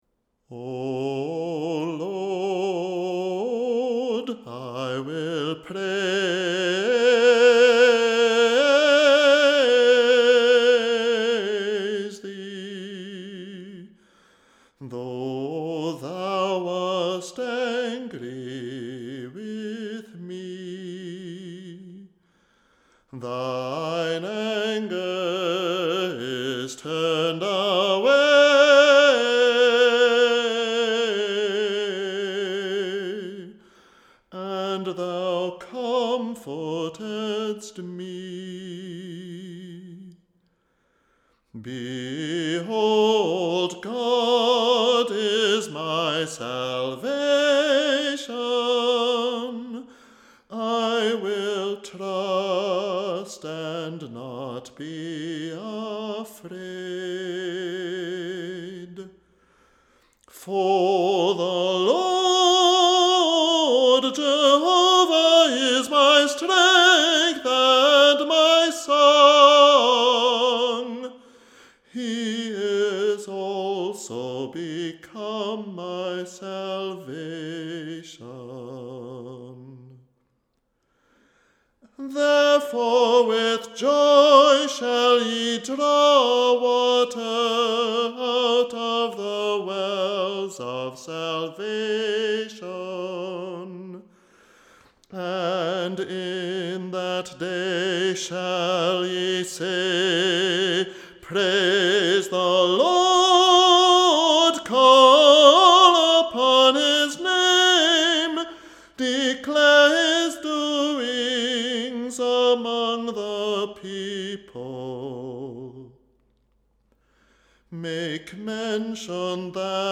vocal solo repertoire